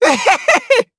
Kibera-Vox_Attack4_jp.wav